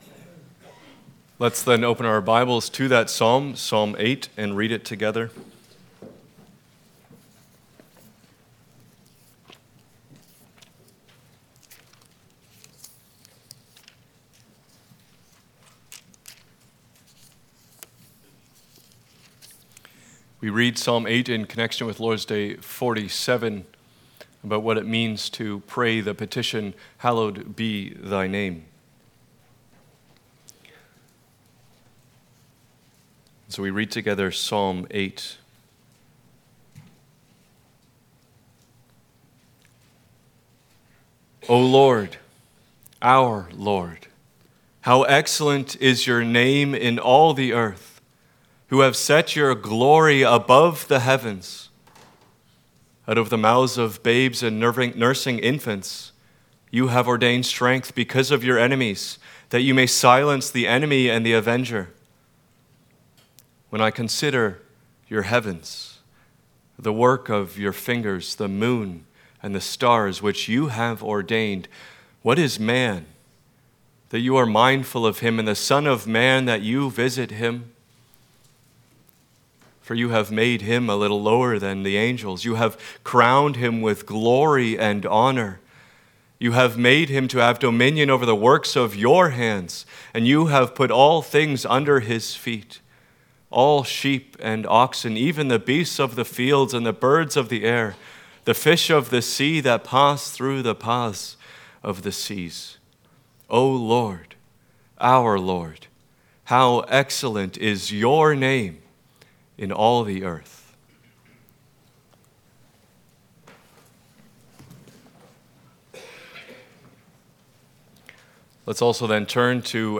General Passage: Psalm 8 Service Type: Sunday Afternoon « The Lord Finds His People’s Saviour Rejoice